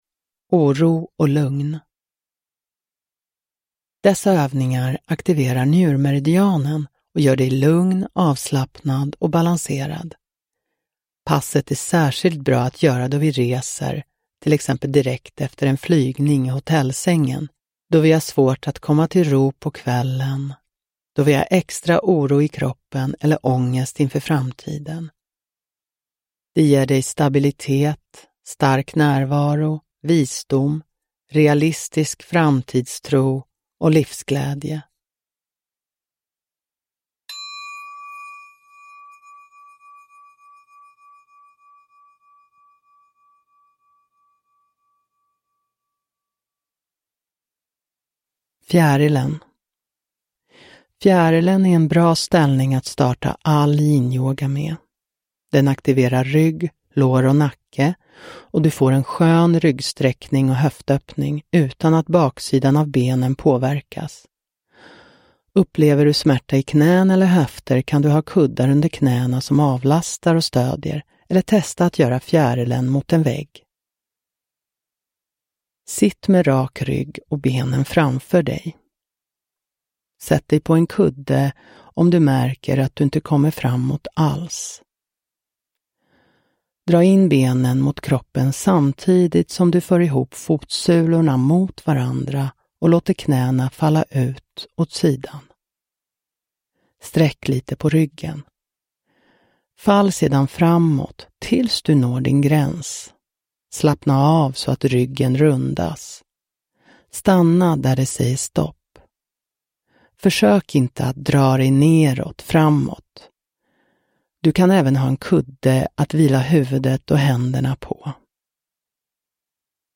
Oro och lugn – Ljudbok – Laddas ner